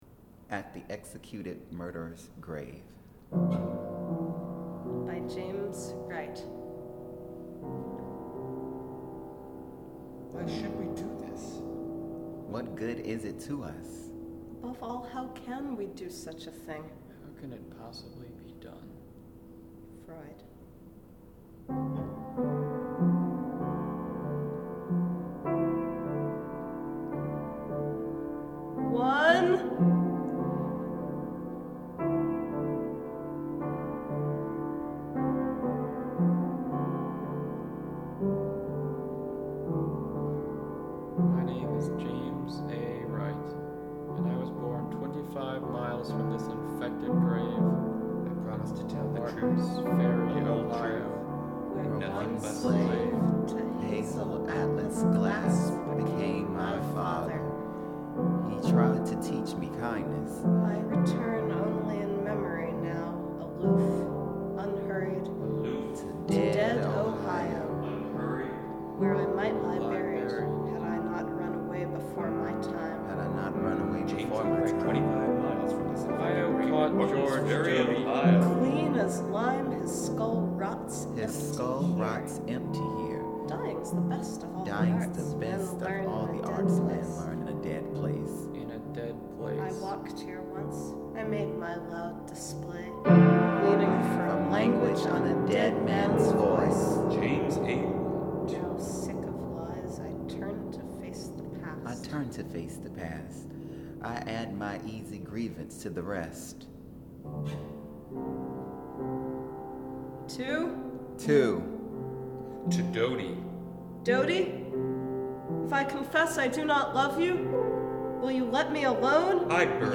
Four voices, one piano, and the Marantz dramatically cutting out seconds before the end of the poem and the word “underground.” Lots of vocal complexity: decoration, echoing, improvising.